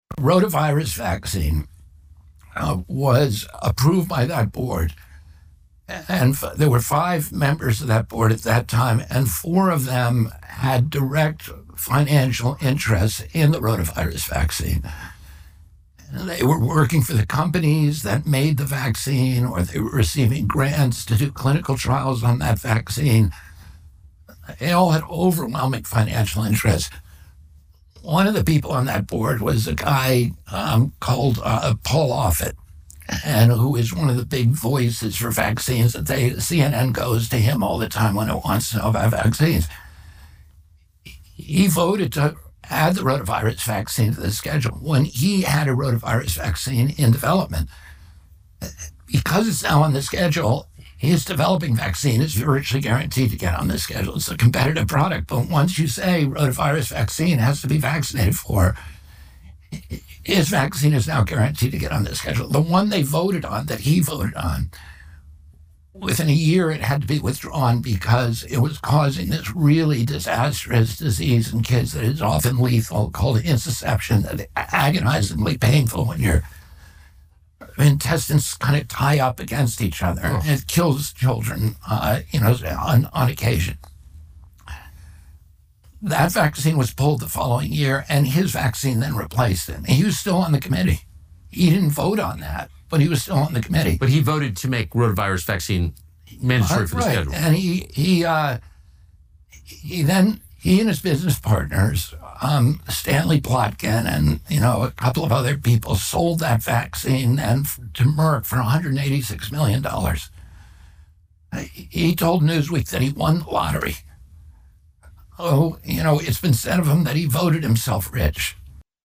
RFK Jr. Interview:
RFK Jr. did an interview with Tucker Carlson earlier this week where the two discussed vaccines.